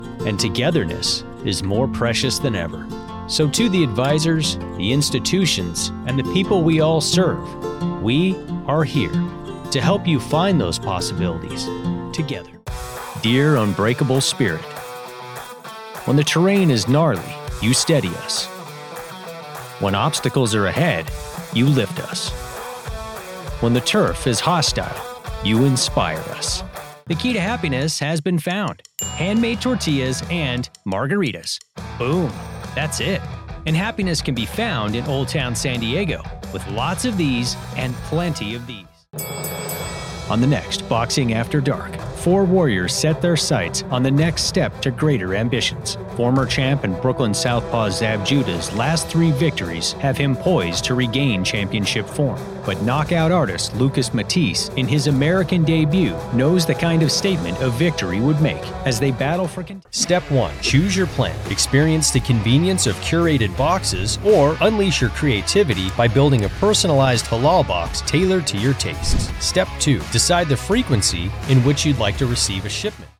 Male Voice Over Talent
Commercial Demo
General American